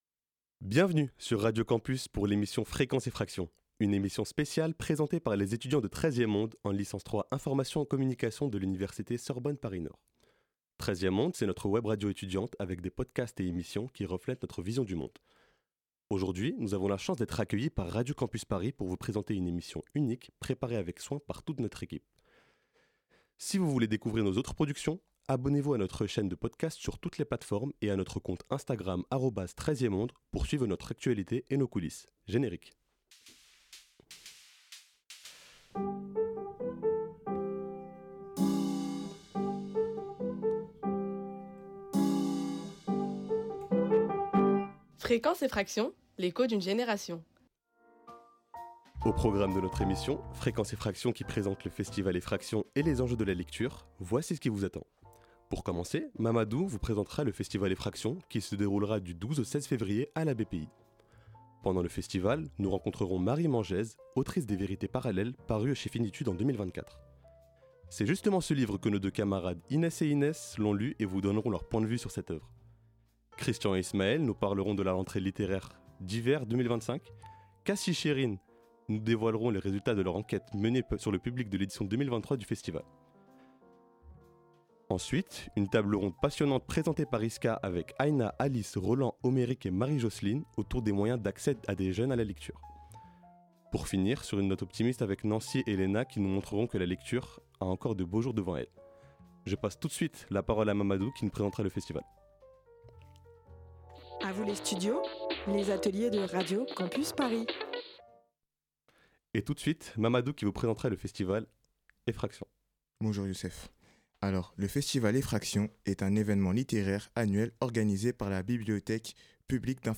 A l'occasion du festival de littérature contemporaine Effractions, qui se déroulait du 12 au 16 février 2026 à la BPI du Centre Pompidou, les étudiantes et les étudiants en Licence 3 information et communication de l'université Sorbonne Paris Nord ont préparé une grand émission littéraire.